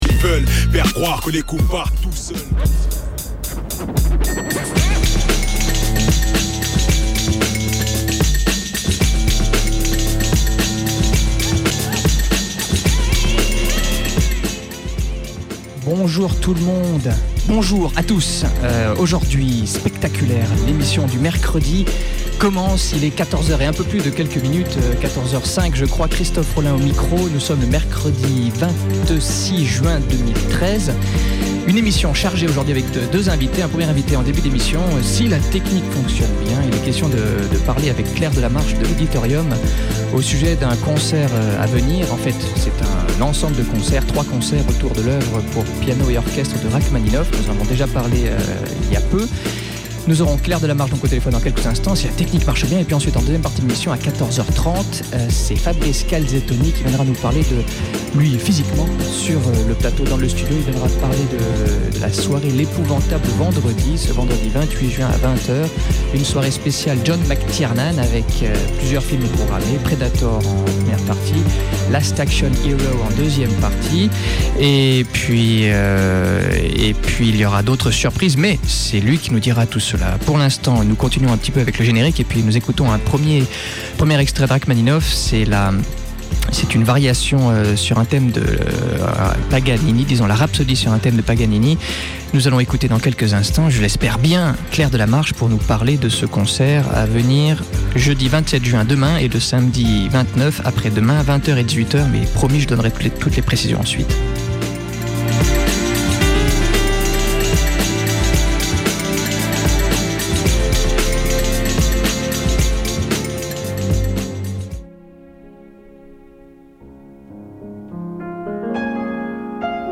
Deux invités